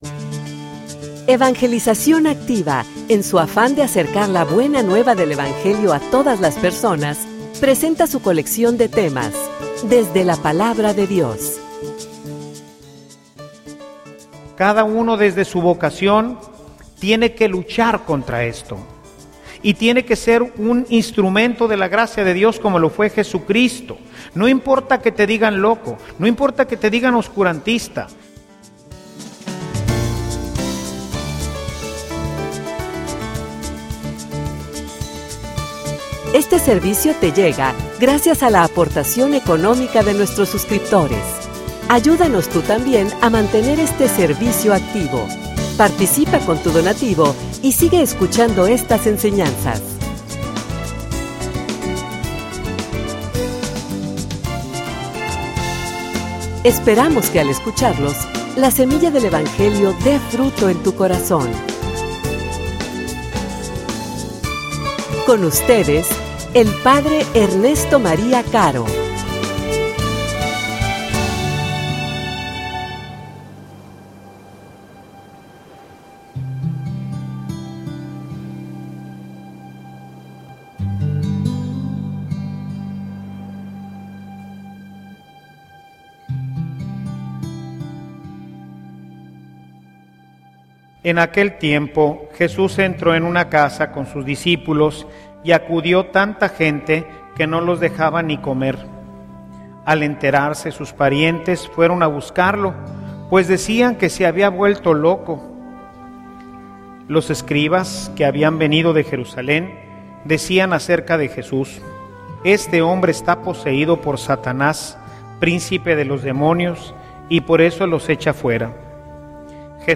homilia_Creian_que_estaba_loco.mp3